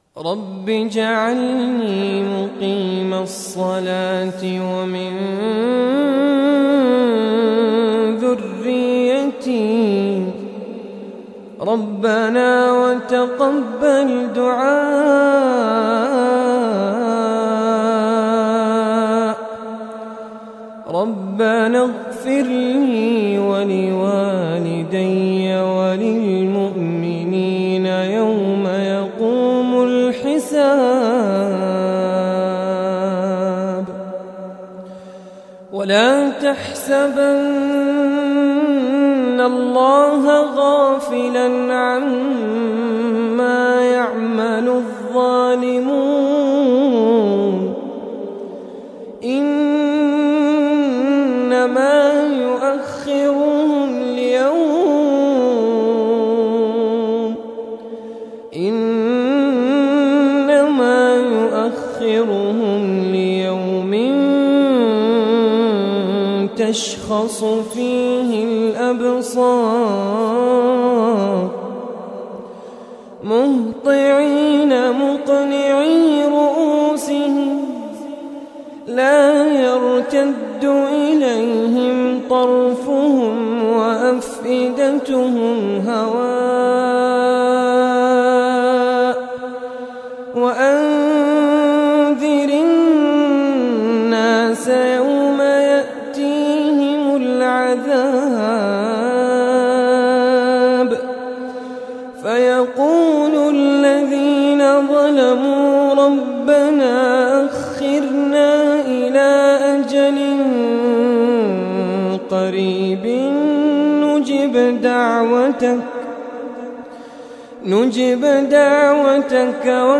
تلاوة من سورة إبراهيم للقارئ